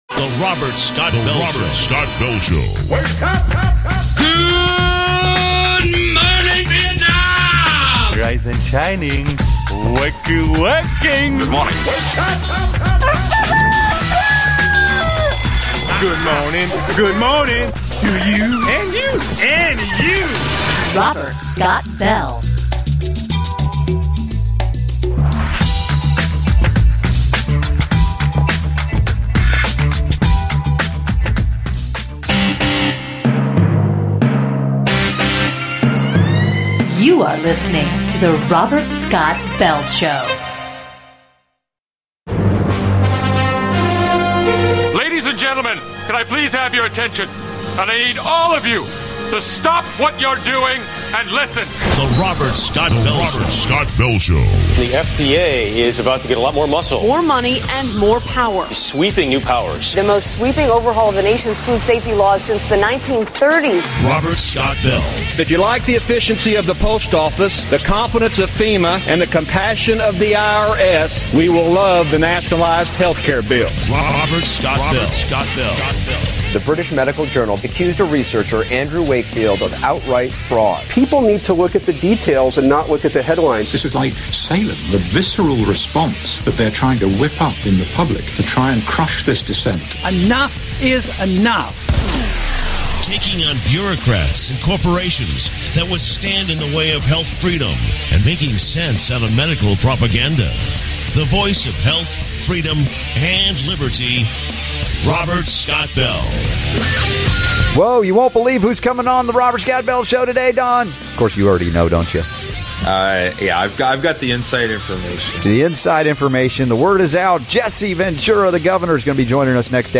Jesse Ventura talks about his lawsuit against TSA